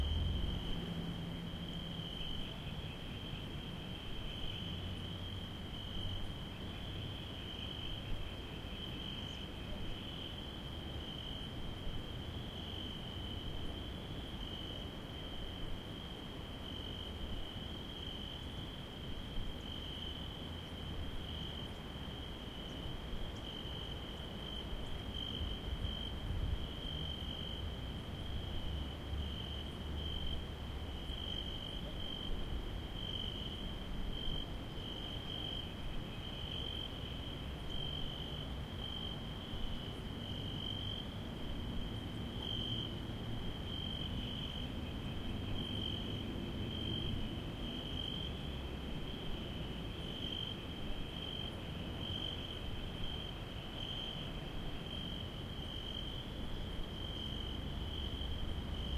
nightLoop.ogg